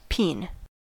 Ääntäminen
IPA : /piːn/